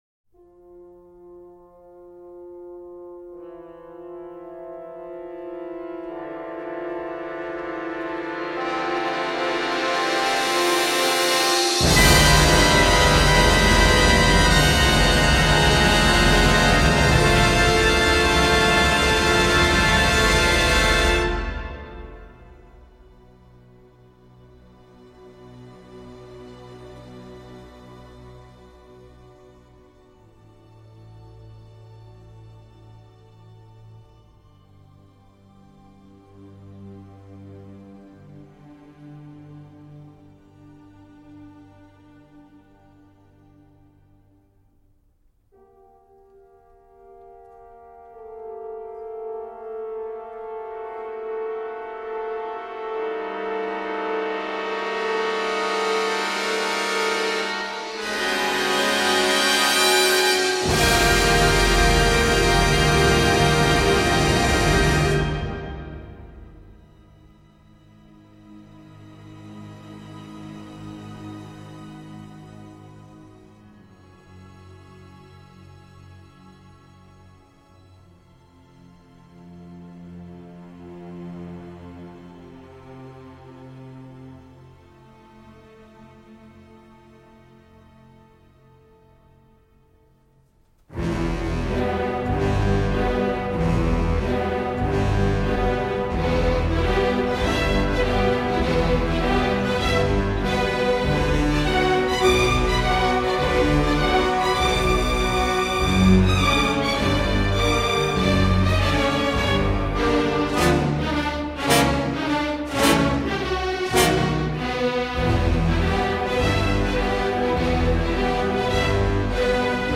The piece of music’s sense of foreboding and knives-out tension is perfect for a pre-sacking amuse-bouche.
Philadelphia Orchestra cond. by Riccardo Muti